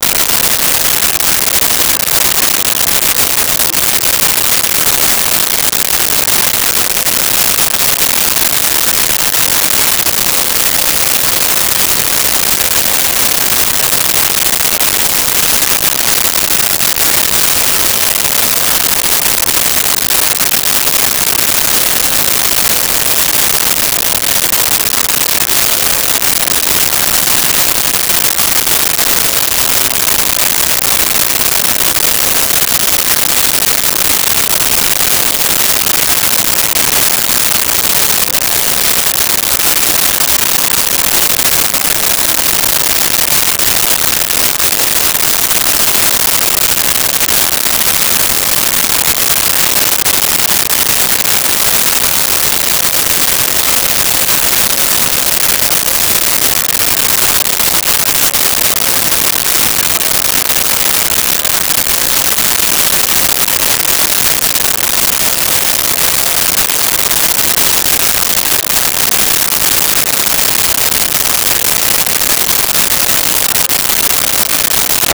Casino Interior
Casino Interior.wav